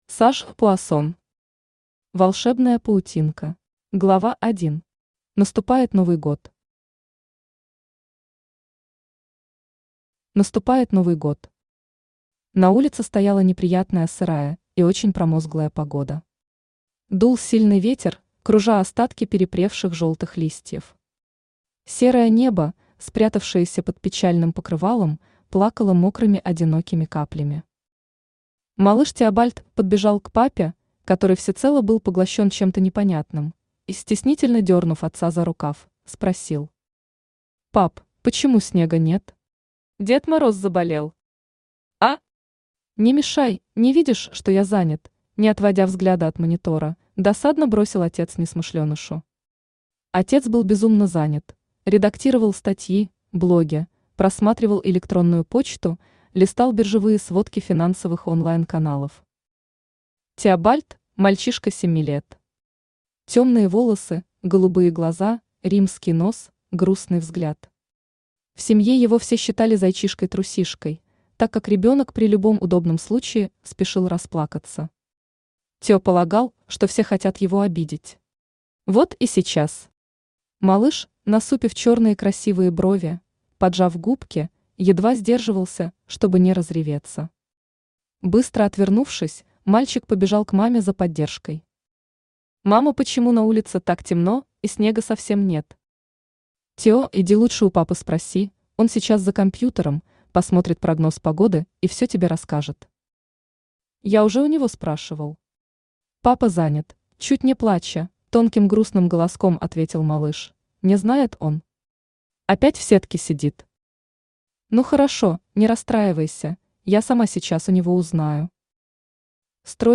Аудиокнига Волшебная паутинка | Библиотека аудиокниг
Aудиокнига Волшебная паутинка Автор Саж Пуассон Читает аудиокнигу Авточтец ЛитРес.